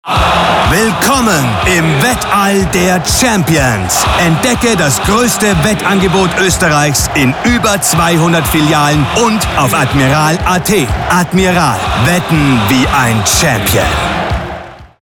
Werbung Admiral